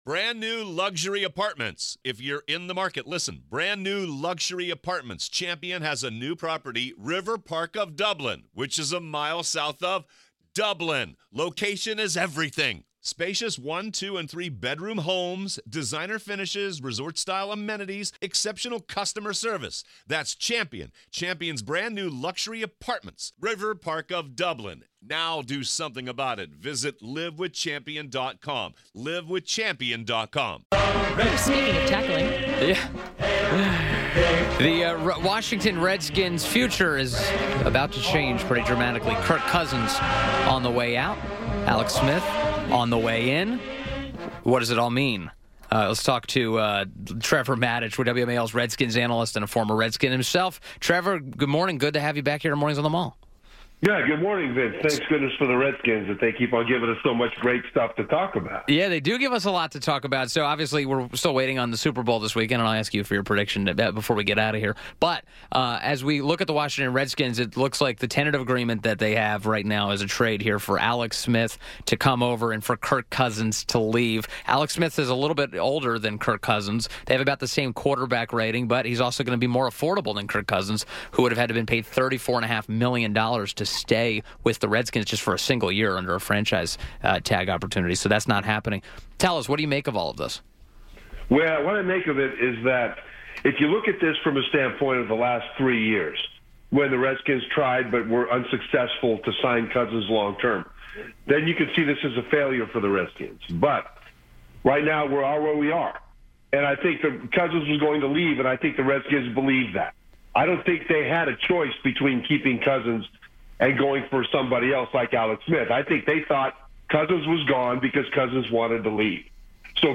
WMAL Interview - Trevor Matich - 02.01.18